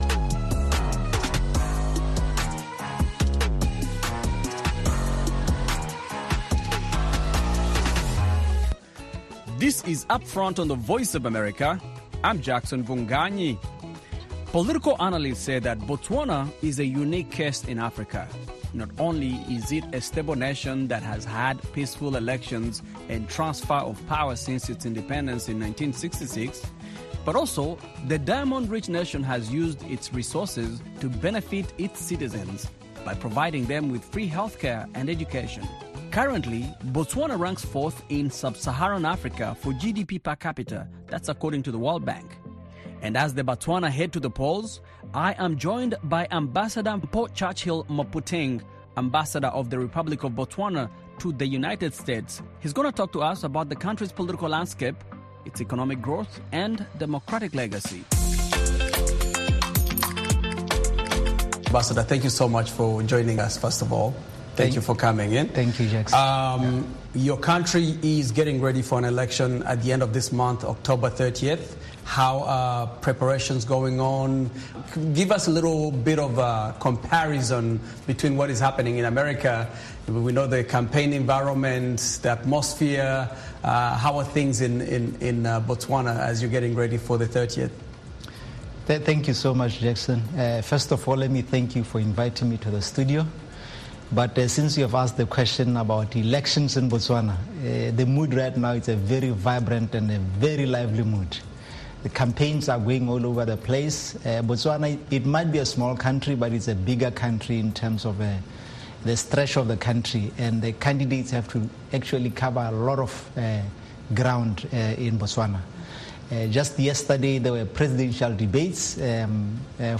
A Conversation with Ambassador Mpho Churchill O. Mophuting Ahead of the Elections